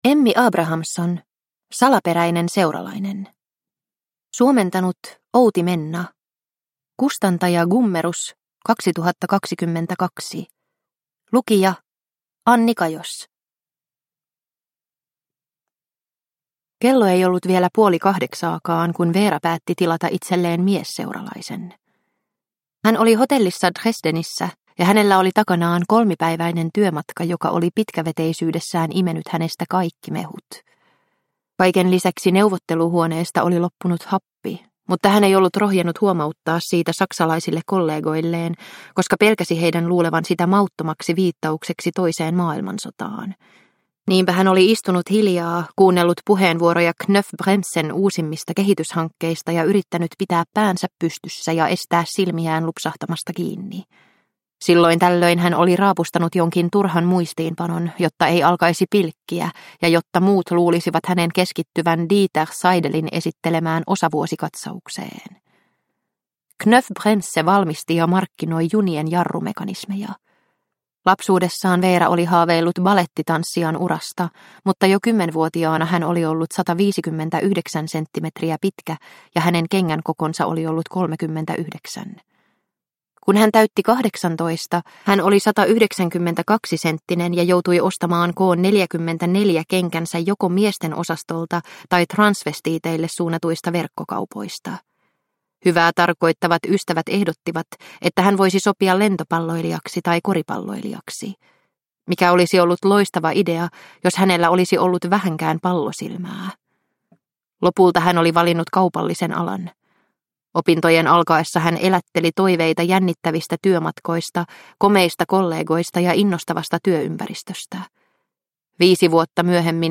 Salaperäinen seuralainen – Ljudbok – Laddas ner